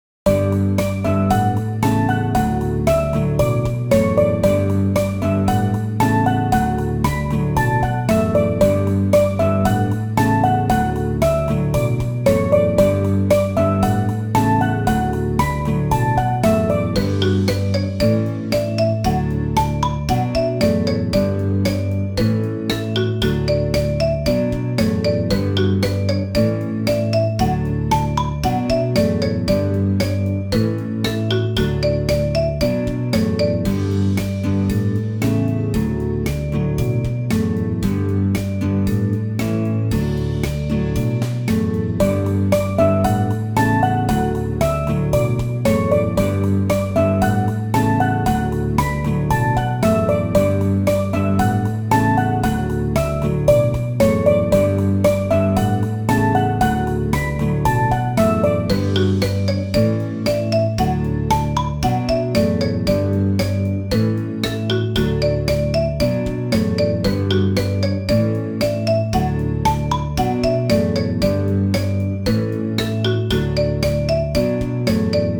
ogg版   明るく楽しい雰囲気のBGMです。スチールドラムと木琴。